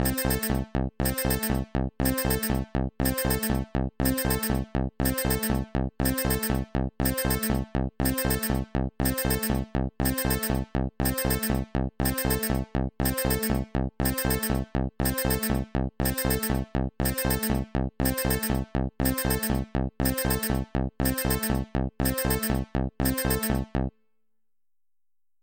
Download Free Retro Game Alarm Sound Effects
Retro Game Alarm